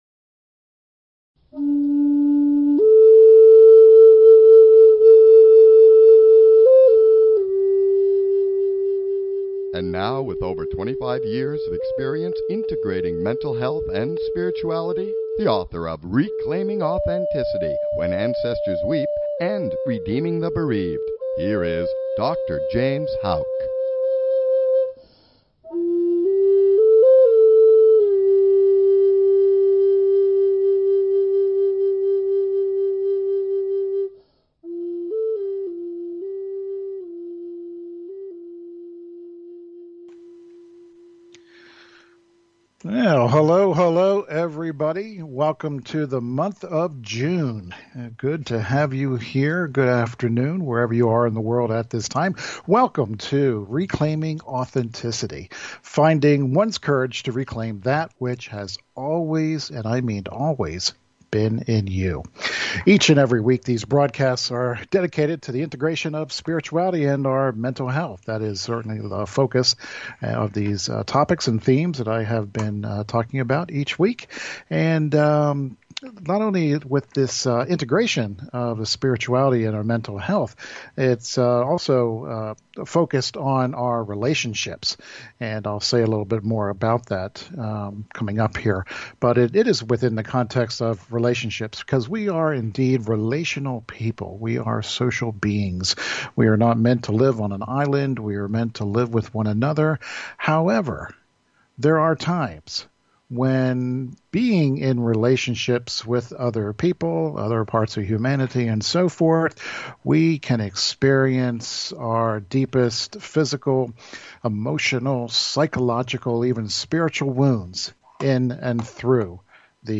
Talk Show Episode, Audio Podcast, Reclaiming Authenticity and One year anniversary show, the alchemy of authenticity, transforming dimensions of spirituality on , show guests , about One year anniversary show,the alchemy of authenticity,transforming dimensions of spirituality, categorized as Health & Lifestyle,Kids & Family,Philosophy,Psychology,Emotional Health and Freedom,Personal Development,Self Help,Society and Culture,Spiritual